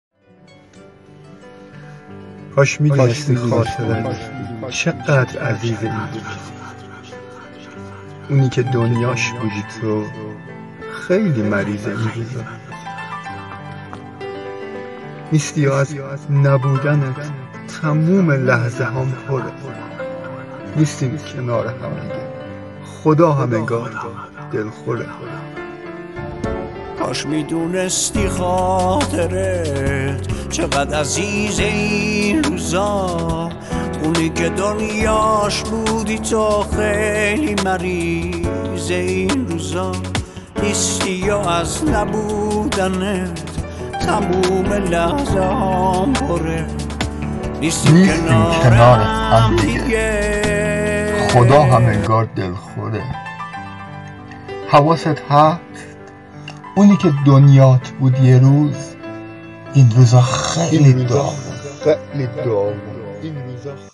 بازخوانی با صدای مرد